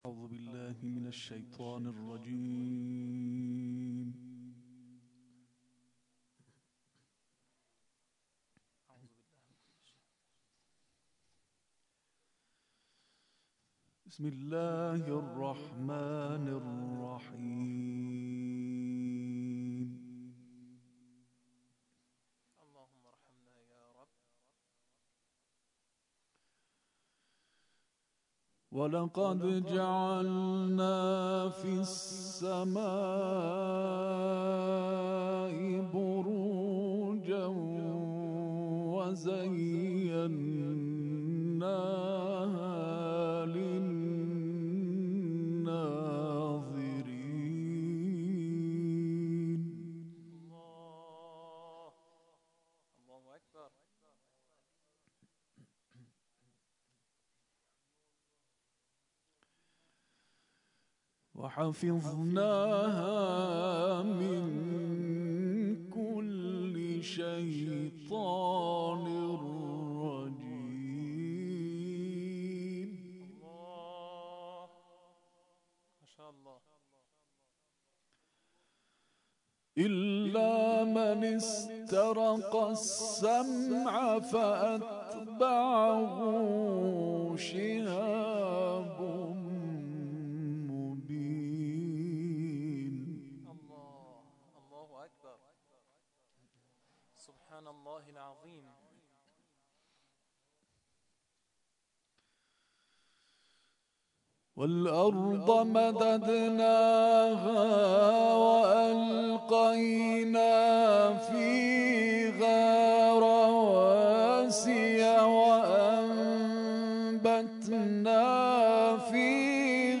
در ادامه تلاوت این قاری بین‌المللی را می‌شنوید.